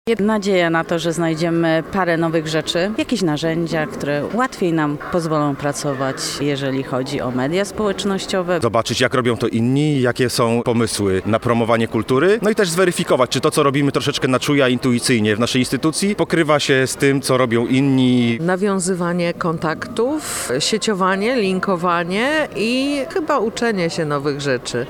Z uczestnikami konferencji Marketing w Kulturze rozmawiał nasz reporter.